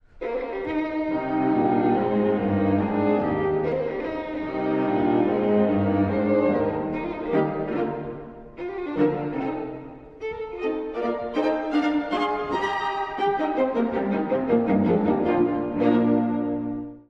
古い音源なので聴きづらいかもしれません！（以下同様）
流麗な16分音符のモチーフに乗って、Es-durのメロディーが颯爽と現れます。
この楽章の特徴は、この16分音符とともに奏でられる上昇音型の数々
深い調性ながらも、前向きな活気が感じられます。
また、弱拍から始まるフレーズが多いのも特徴。